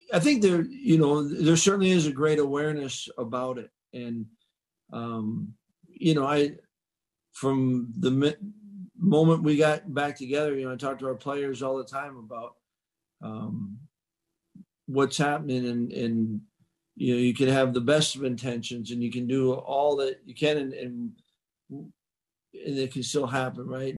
Chryst spoke to the media on Wednesday about the state being a COVID-19 hotspot, and what that meant for his team going forward.